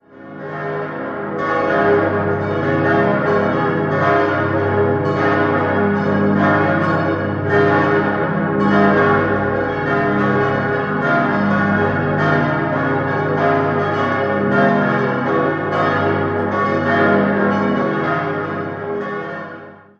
Das rot leuchtende Chorfenster stammt von Augusto Giacometti. 6-stimmiges Geläute: as°-des'-es'-f'-as'-des'' Alle Glocken wurden im Jahr 1906 von der Gießerei Rüetschi in Aarau gegossen.